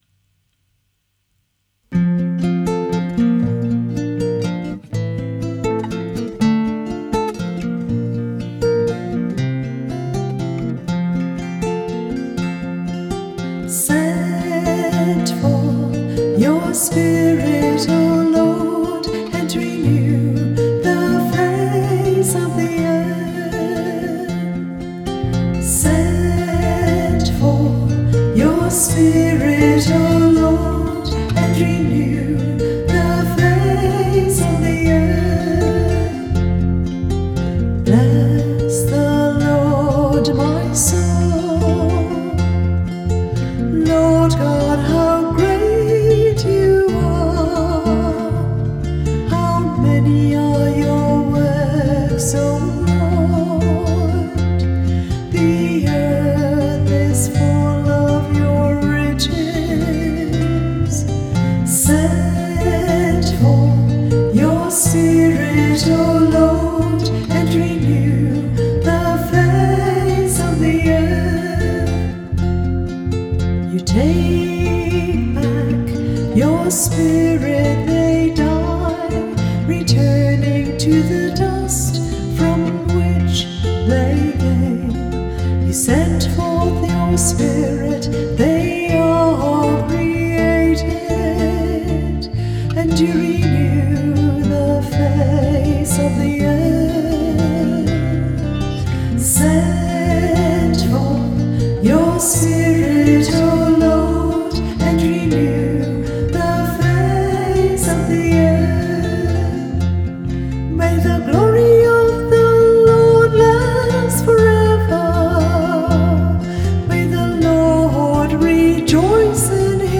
The music for the psalm is an original OLOR composition.
Music by the Choir of Our Lady of the Rosary RC Church, Verdun, St. John, Barbados.